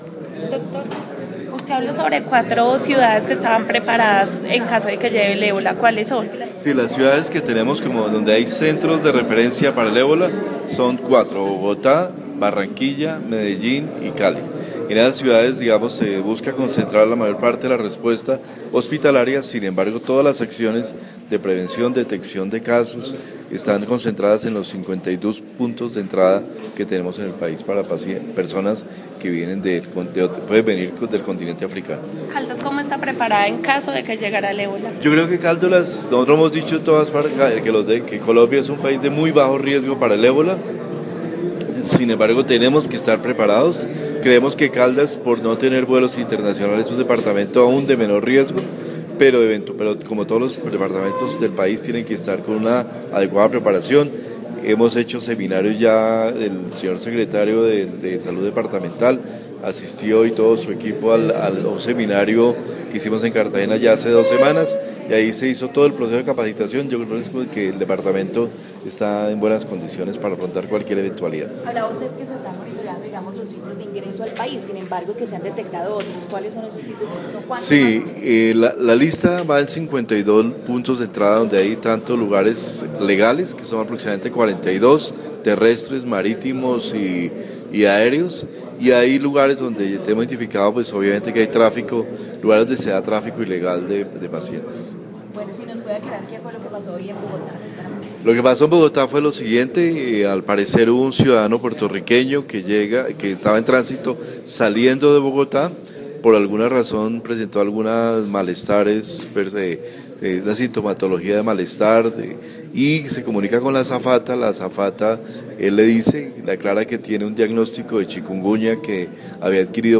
Viceministro de Salud, Fernando Ruiz Gómez
Audio: ViceSalud habla de la farsa alarma de ébola en Bogotá